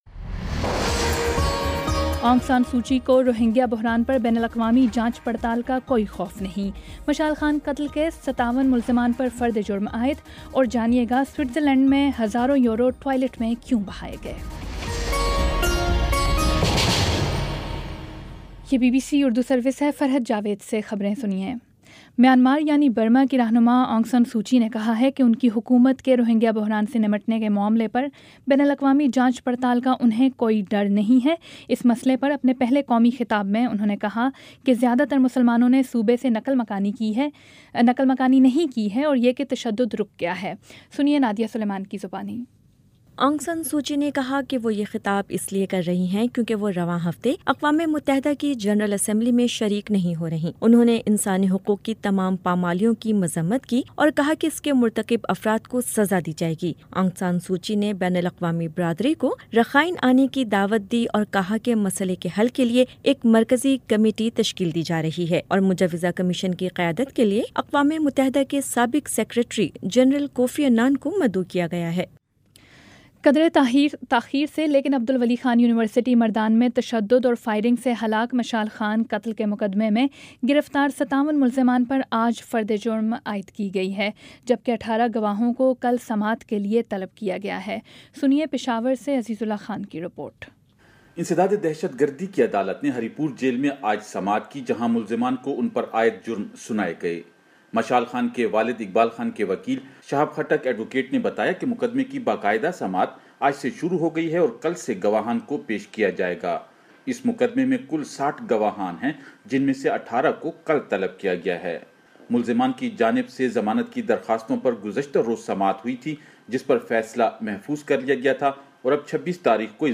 ستمبر 19 : شام پانچ بجے کا نیوز بُلیٹن